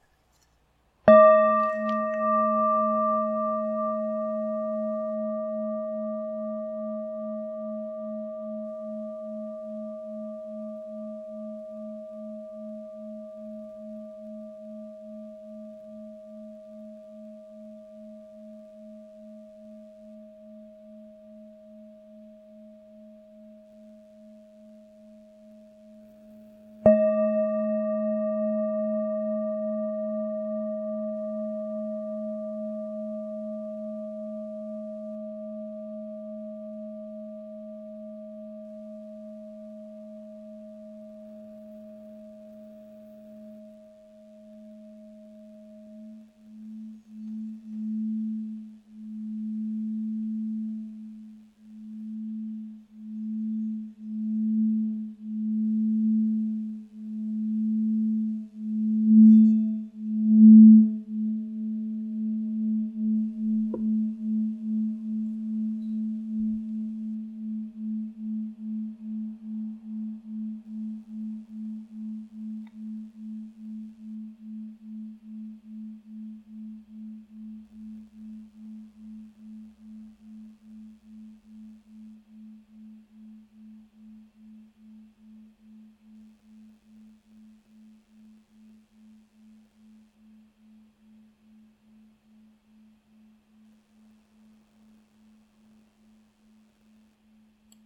Nota Armonica RE(D) #5 626 HZ
Nota di fondo LA(A) 3 220 HZ
Campana Tibetana Nota LA(A) 3 220 HZ